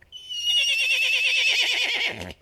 horseman_die2.wav